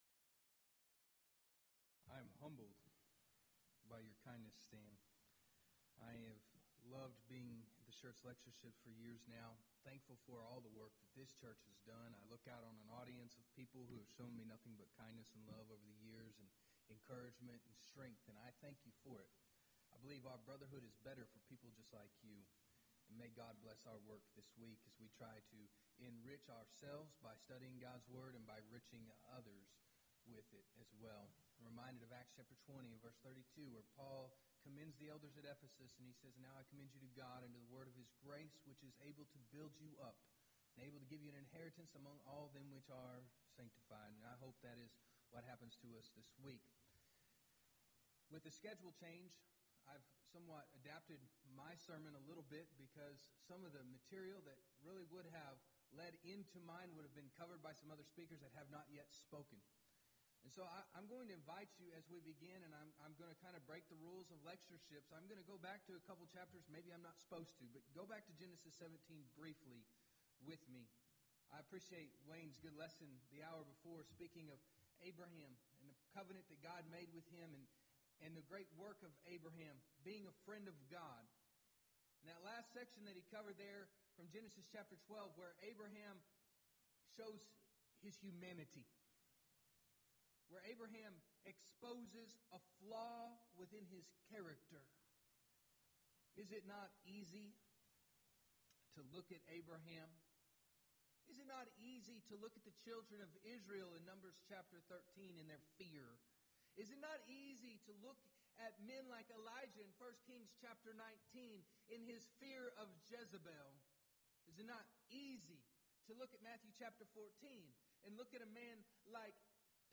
Series: Schertz Lectureship Event: 16th Annual Schertz Lectures